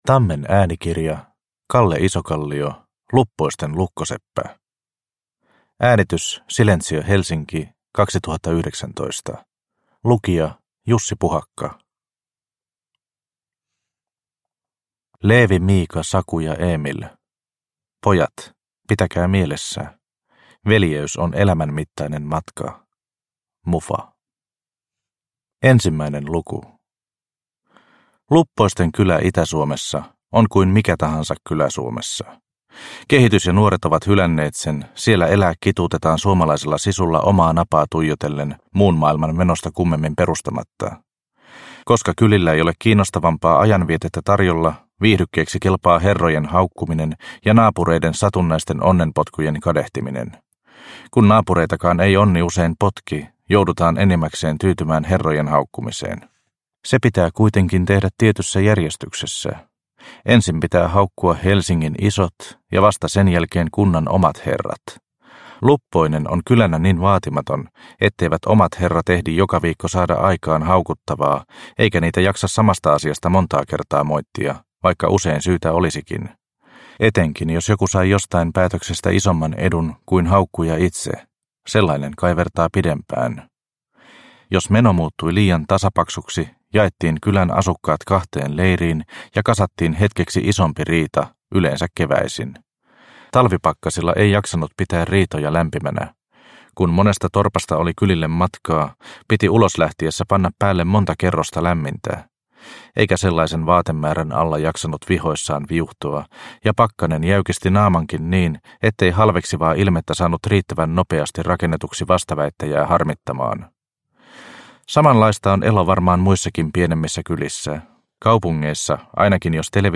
Luppoisten lukkoseppä – Ljudbok